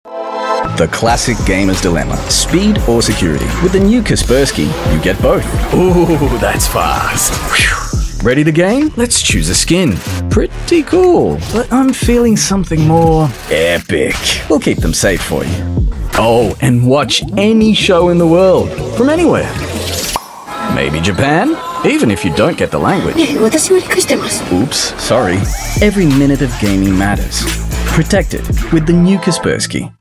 Male
Television Spots
Words that describe my voice are Deep, Tenor, Credible.